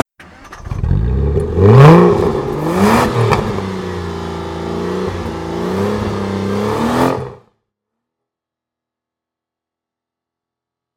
motore.wav